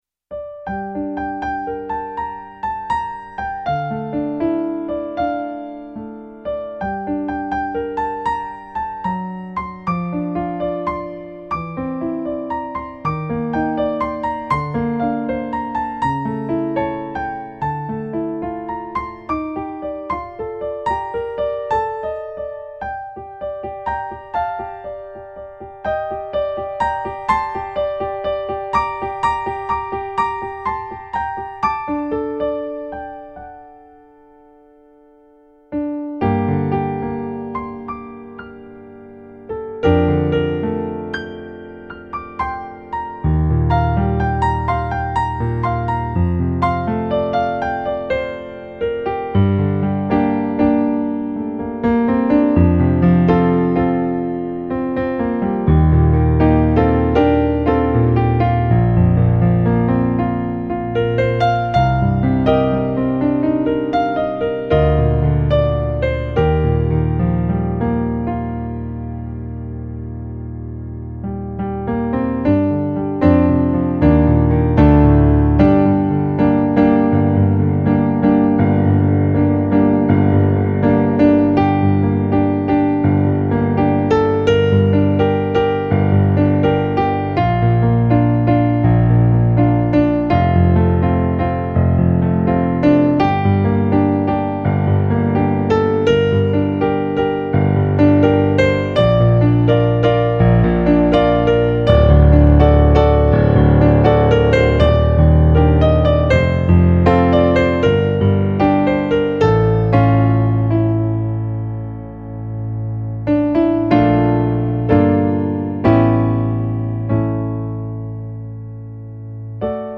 eight piano solo arrangements.  31 pages.
New Age remix